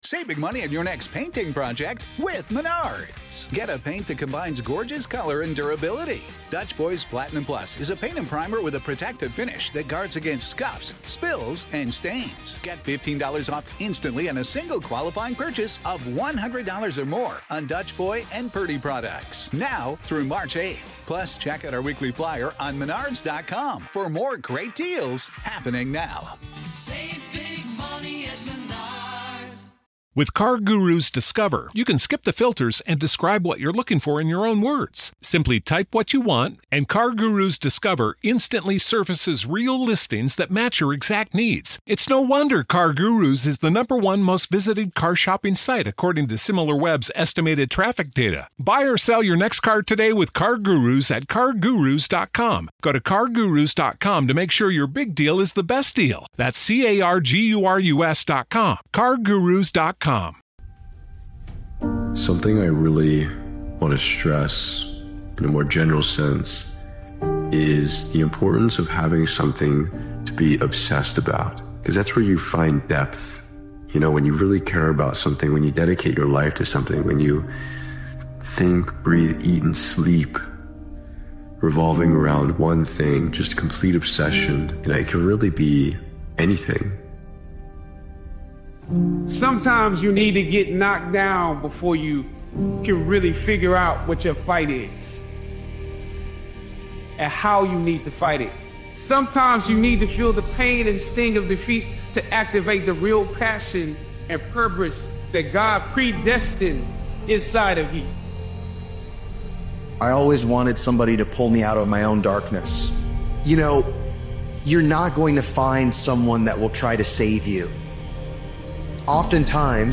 This impactful motivational speeches compilation reminds you that confidence isn't given-it's earned.